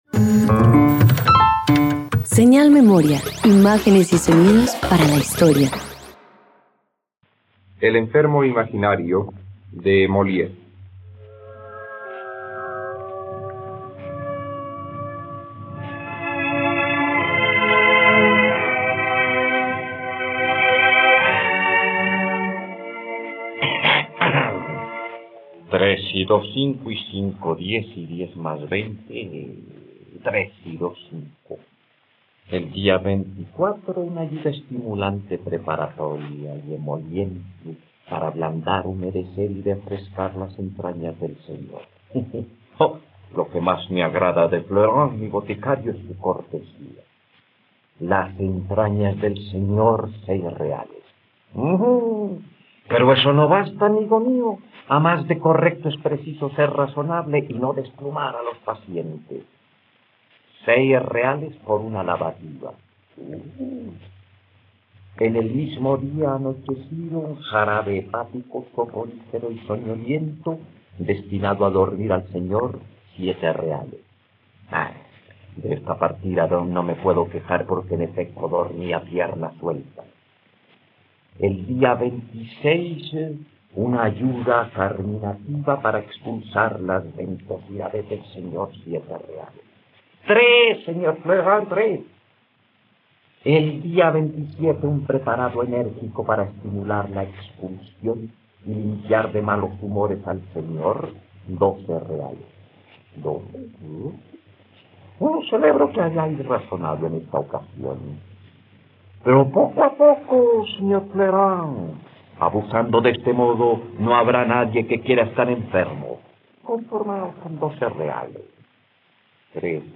..Radioteatro. Escucha la adaptación para radio de la comedia “El enfermo imaginario" del dramaturgo francés Molière en la plataforma de streaming RTVCPlay.